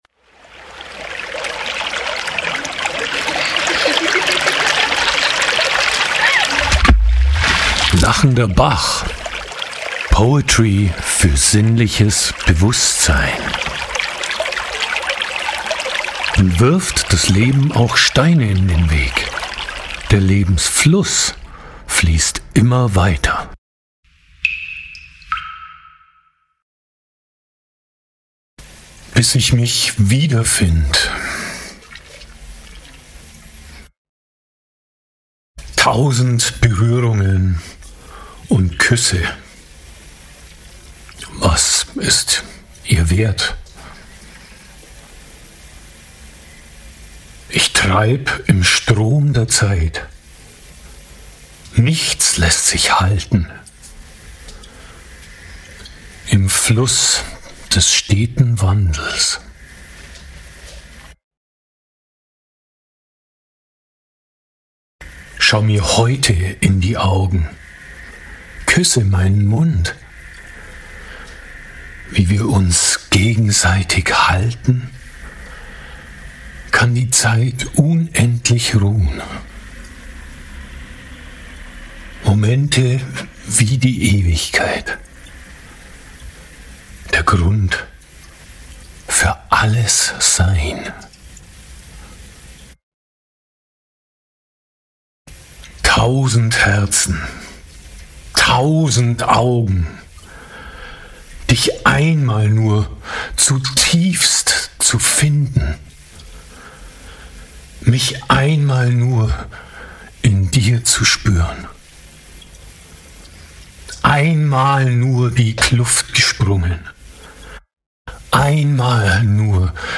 00:12:38 Outro Weibliche Stimme